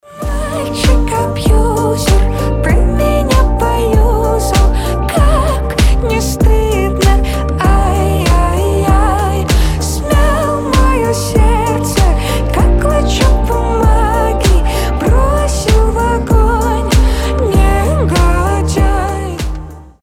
• Качество: 320, Stereo
женский голос